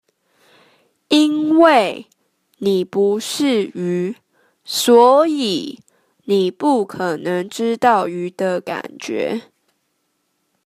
(請先仔細聆聽老師唸，再來，請你試著唸唸看。)